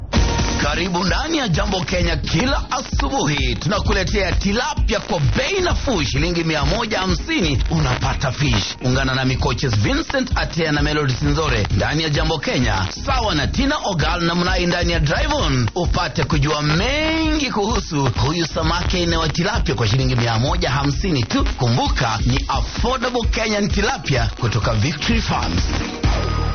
Station: Citizen FM
Brand: Victory Farms Advert: Fresh Fish